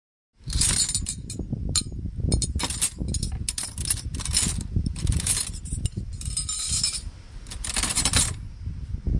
碗碟
描述：菜肴在水槽里移动。倾吐在杯子外面的水。模拟某人做菜。
标签： 厨房 餐具 清洁 银器 水刀 铿锵 勺子 金属 餐具 铮铮
声道立体声